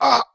initiate_die2.wav